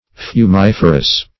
Search Result for " fumiferous" : The Collaborative International Dictionary of English v.0.48: Fumiferous \Fu*mif"er*ous\, a. [L. fumifer; fumus smoke + ferre to bear.] Producing smoke.